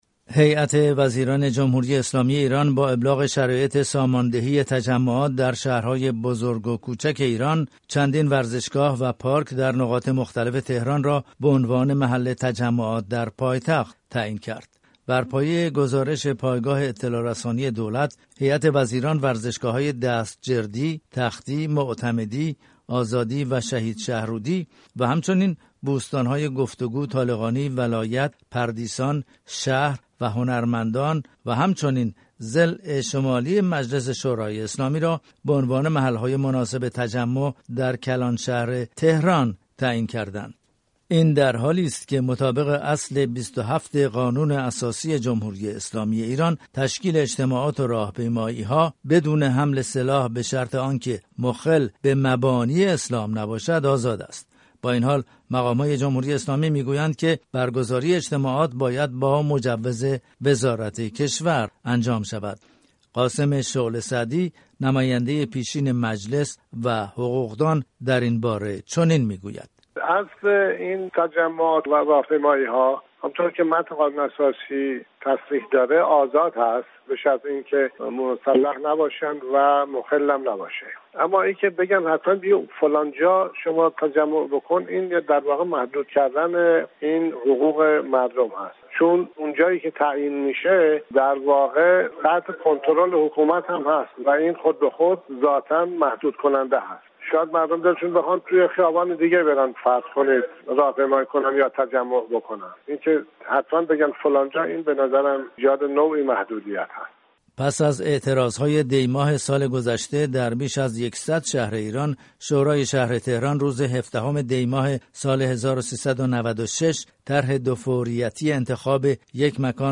ساماندهی تجمعات در تهران و چالش‌های قانونی درگفت‌وگو با یک حقوقدان و جامعه‌شناس
اما آیا این اقدام دولت روحانی با قانون اساسی ایران در تضاد نیست؟ این سوال را با یک نماینده پیشین مجلس و حقوقدان و همچنین یک جامعه شناس در میان گذاشتیم.